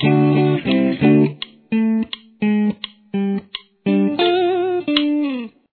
Verse Riff
Here it is a bit slower: